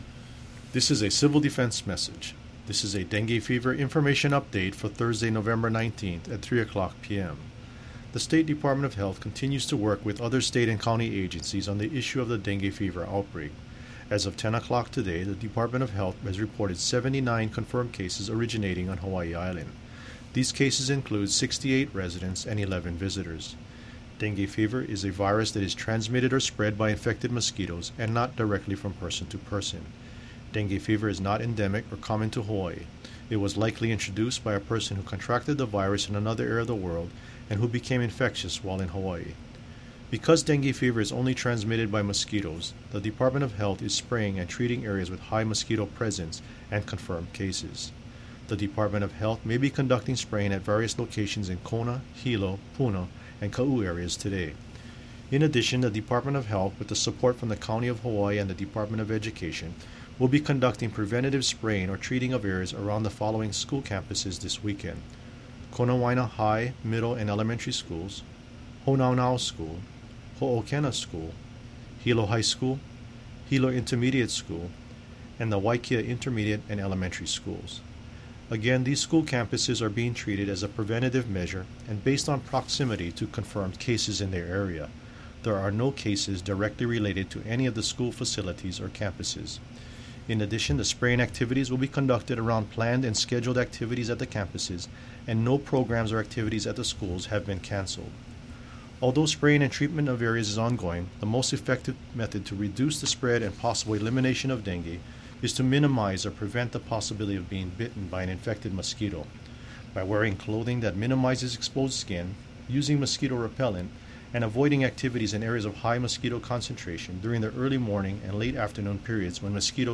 HAWAII: In a press conference held today on Oahu, Governor David Ige said the state has been in communication with the federal Center For Disease Control and Prevention from the beginning, and that the White House is monitoring the situation in Hawaii.